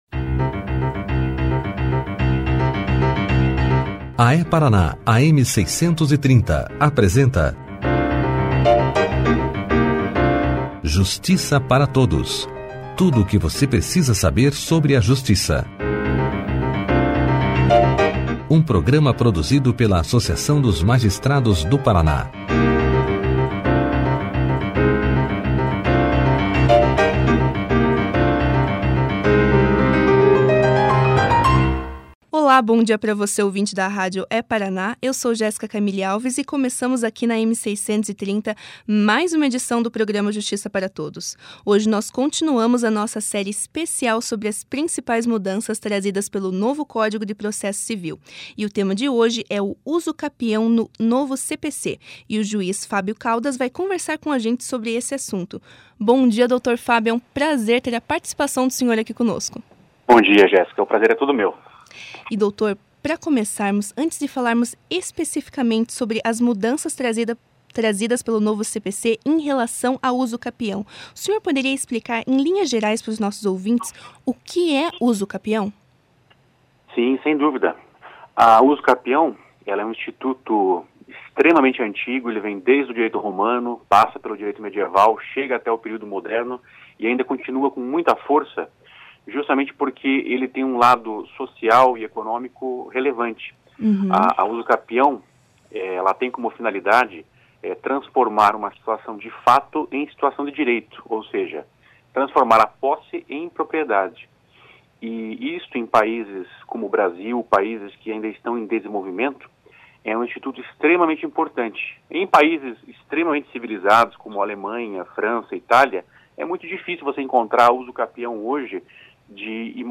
Clique aqui e ouça a entrevista do juiz Fábio Caldas de Araújo sobre a usucapião no Novo Código de Processo Civil na íntegra.